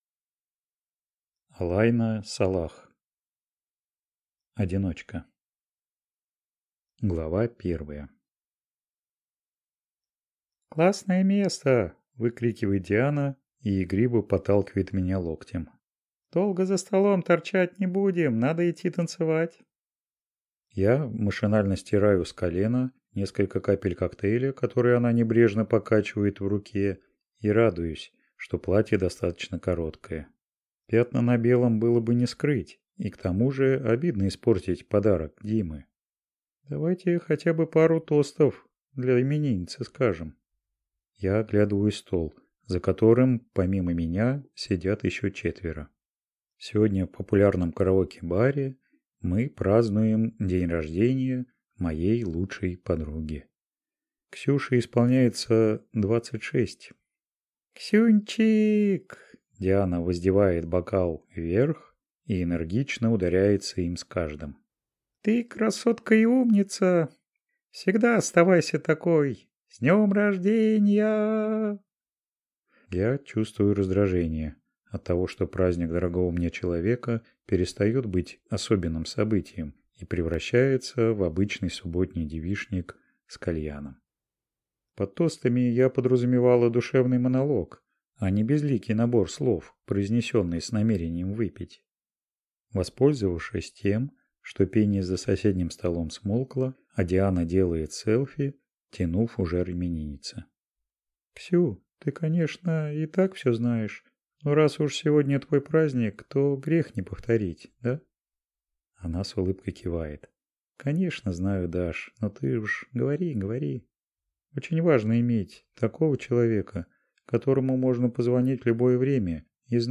Аудиокнига Одиночка | Библиотека аудиокниг